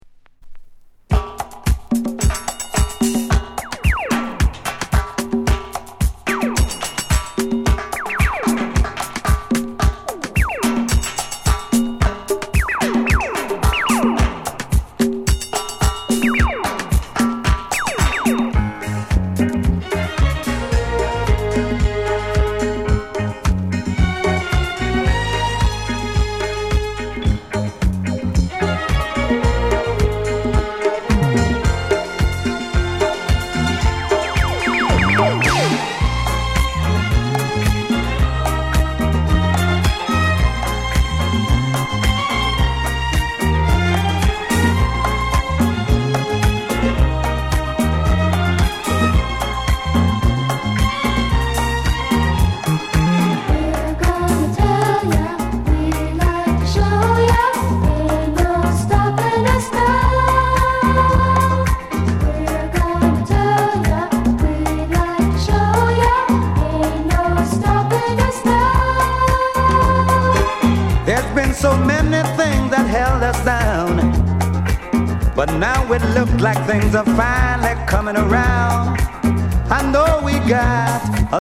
DISCO COVER